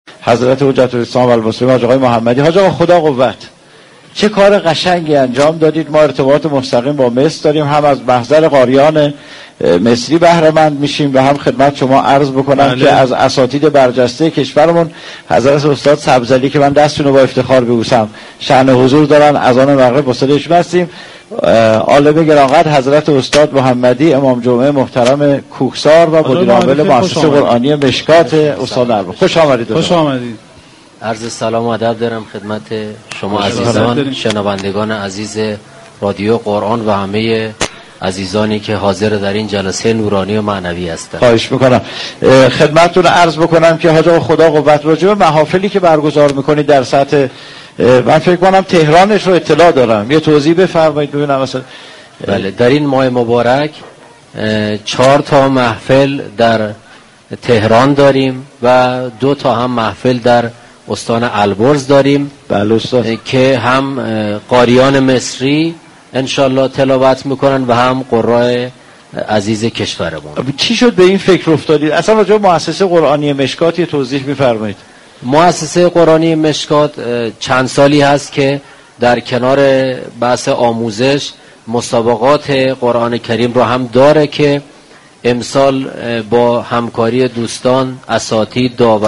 برگزاری محفل انس با قرآن كریم با حضور چهره های برجسته قرآنی در بر آستان جانان رادیو قرآن